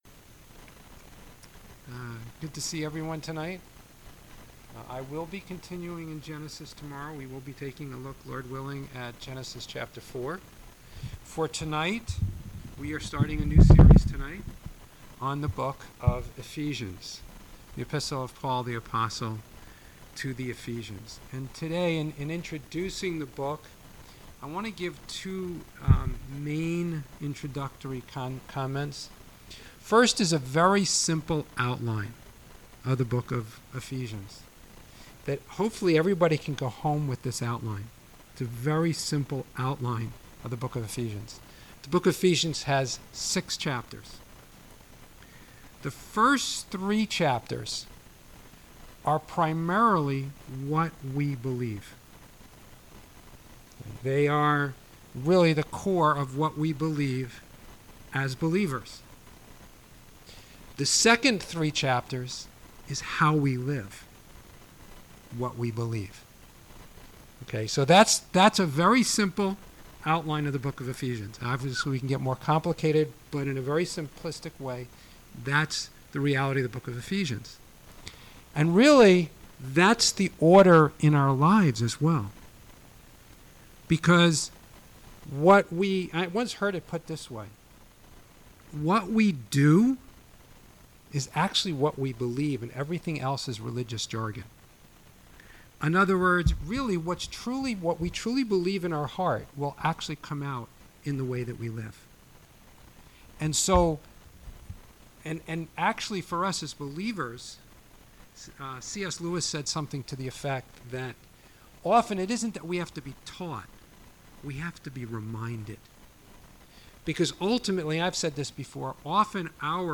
Wealth Beyond Measure Video from Sanctuary Messianic Erev Shabbat Service February 5, 2021 Ephesians 1:1-3 Audio from Sanctuary Messianic Ephesians Introduction, February 5, 2021 Ephesians 1:1-3